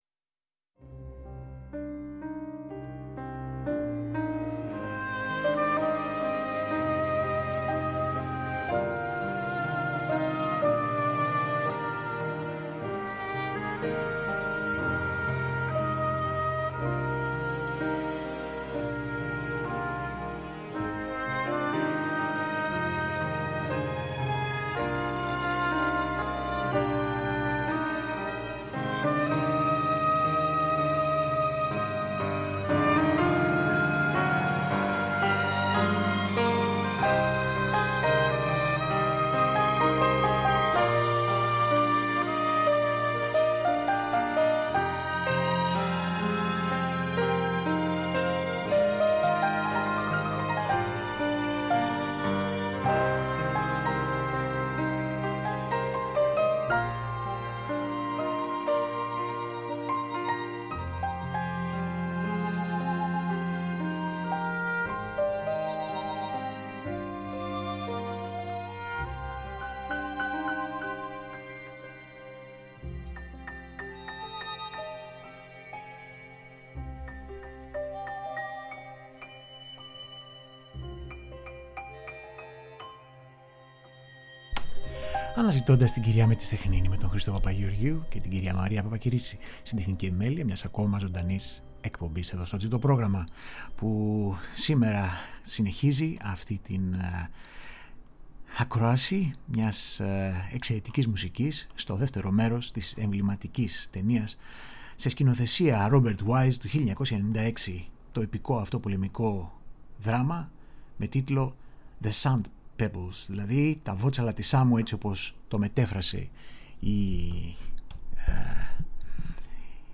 Original Film Score
Κινηματογραφικη Μουσικη